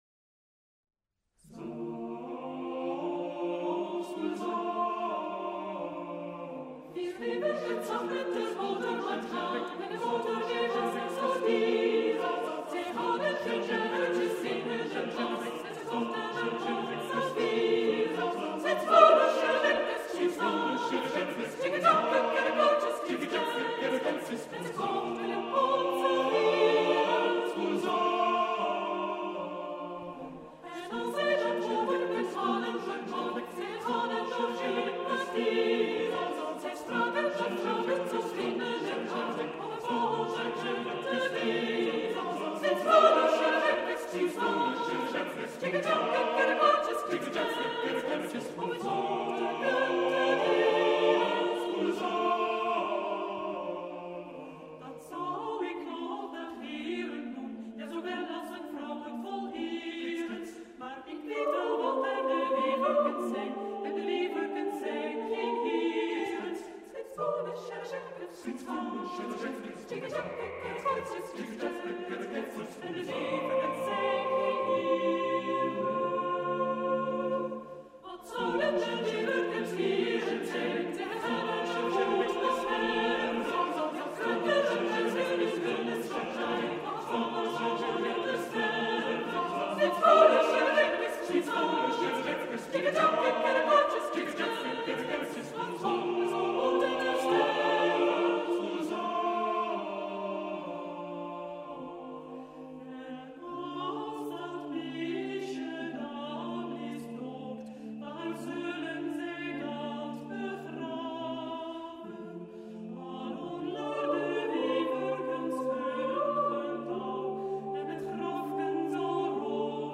Arrangement voor koor.